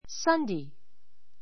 Sunday 小 A1 sʌ́ndei サ ンデイ 名詞 複 Sundays sʌ́ndeiz サ ンデイ ズ 日曜日 ⦣ 週の第1日.